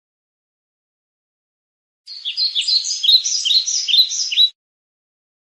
Morning Birds
Morning Birds is a free nature sound effect available for download in MP3 format.
007_morning_birds.mp3